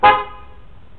４１０Ｈｚ　＋　５１０Hz　＜保安基準適合＞　￥９８０円也
換装後のホーン
ショボショボホーンの　「ミ」　から　「ファ」　に半音グレードアップしました。
９８０円とは思えないヨーロピアンサウンド？！